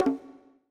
buttonclick.mp3